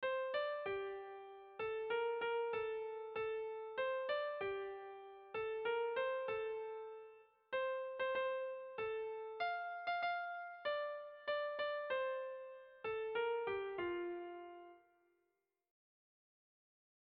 Erlijiozkoa
Lauko ertaina (hg) / Bi puntuko ertaina (ip)
AB